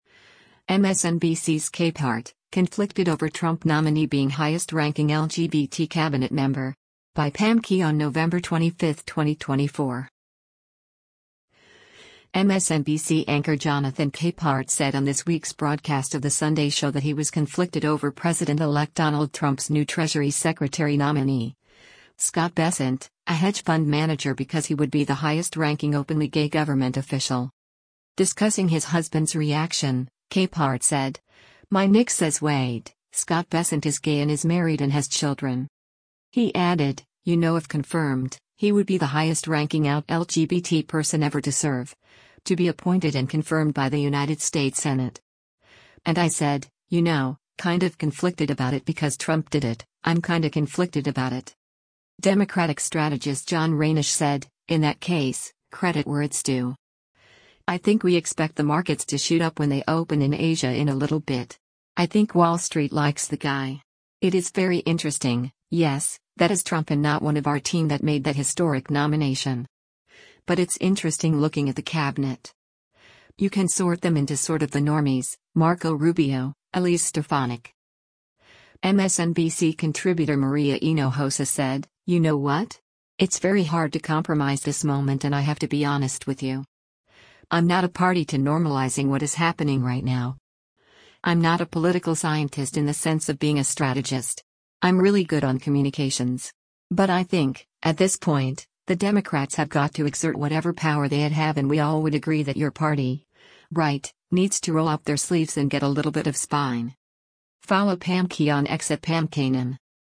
MSNBC anchor Jonathan Capehart said on this week’s broadcast of “The Sunday Show” that he was “conflicted” over President-elect Donald Trump’s new Treasury Secretary nominee, Scott Bessent, a hedge fund manager because he would be the highest-ranking openly gay government official.